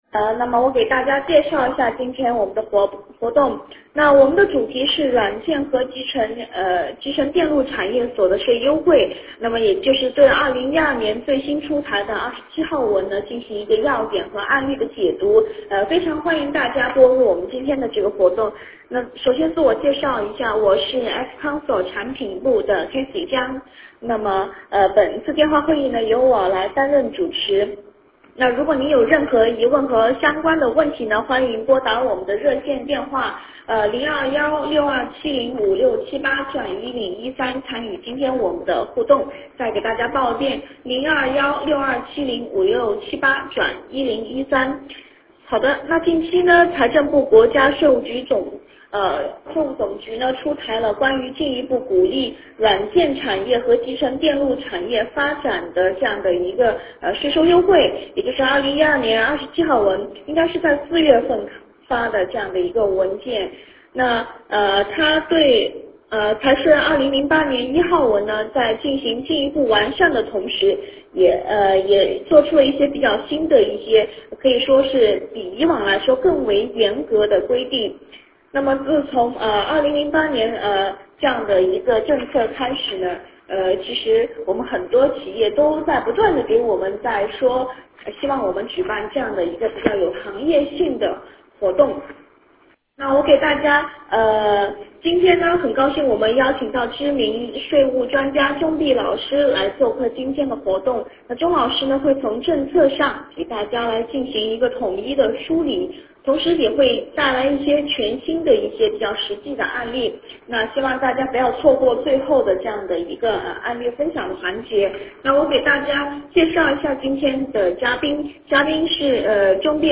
电话会议